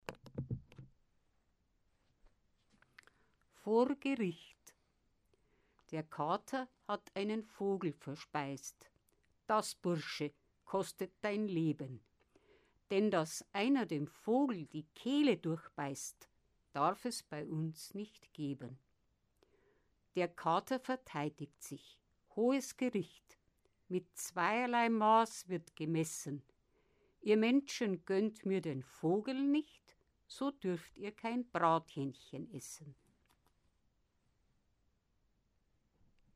Rezitation: